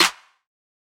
MZ Snareclap [Metro #5].wav